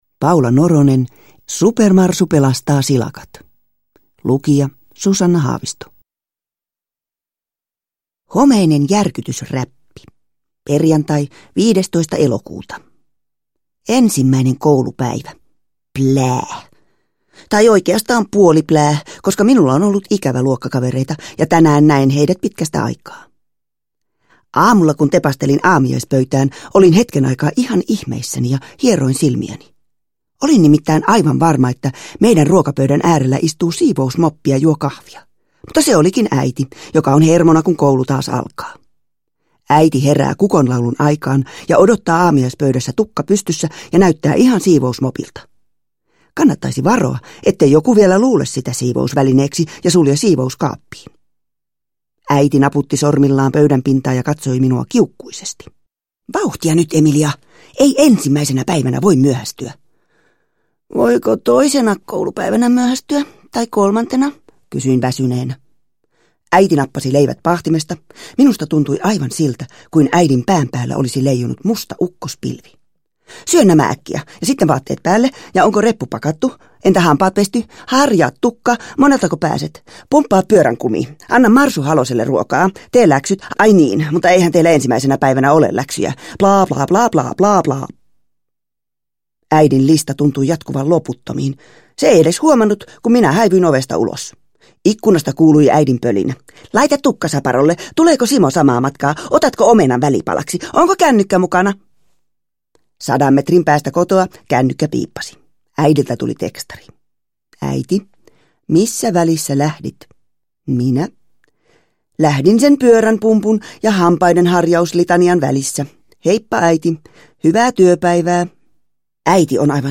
Uppläsare: Susanna Haavisto